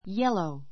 yellow 小 A1 jélou イェ ろウ 形容詞 比較級 yellower jélouə r イェ ろウア 最上級 yellowest jélouist イェ ろウエ スト ❶ 黄色の , 黄色い a yellow flower ♔基本 a yellow flower 黄色い花 ⦣ yellow＋名詞.